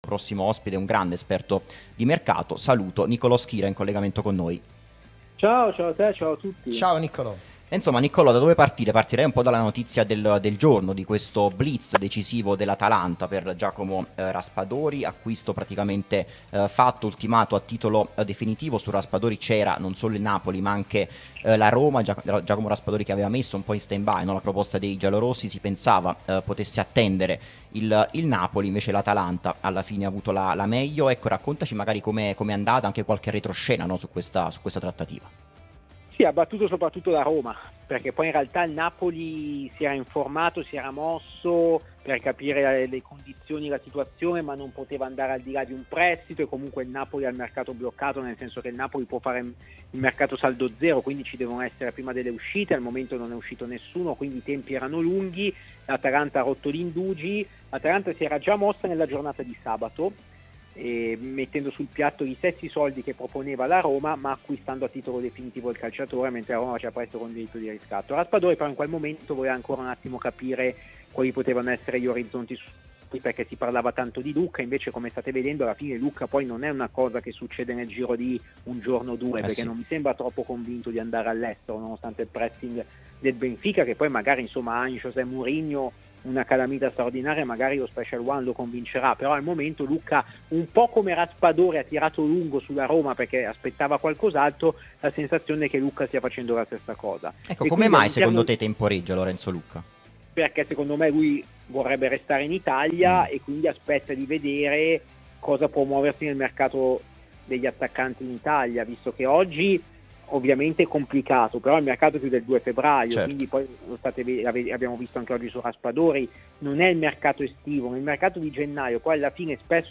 trasmissione sulla nostra Radio Tutto Napoli